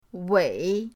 wei3.mp3